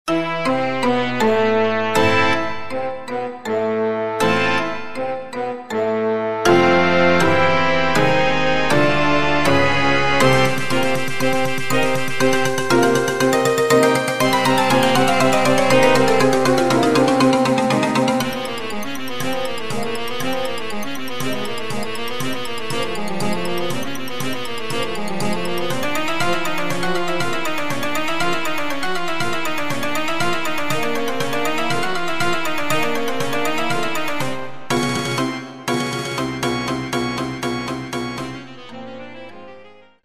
Rozrywkowa